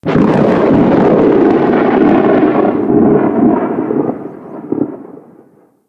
Efecto especial de trueno